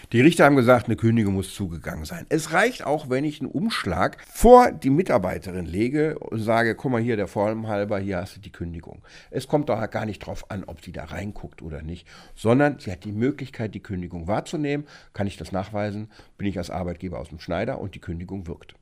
O-Ton: Kündigung auf dem Tisch – das reicht und gilt als zugegangen!